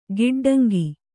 ♪ giḍḍaŋgi